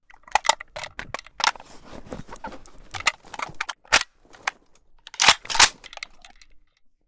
bizon_reload_empty.ogg